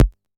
RDM_TapeA_SR88-Perc.wav